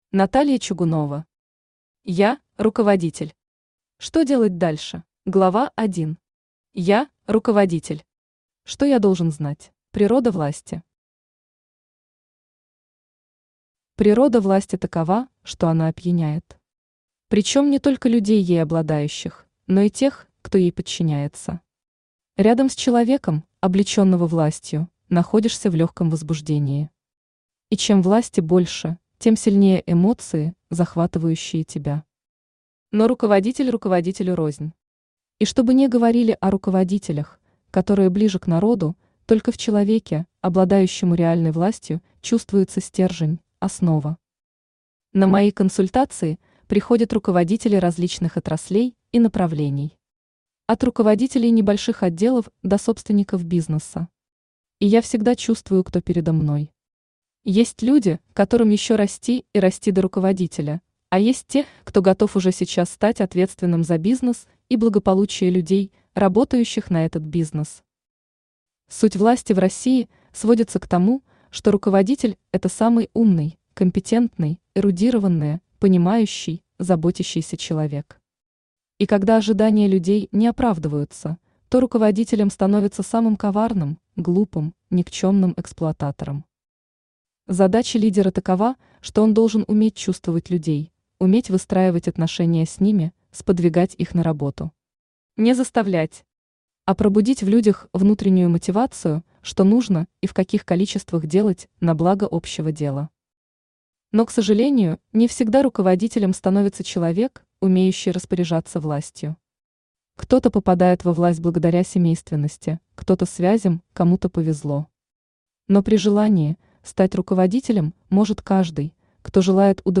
Аудиокнига Я – руководитель! Что делать дальше?
Автор Наталья Чугунова Читает аудиокнигу Авточтец ЛитРес.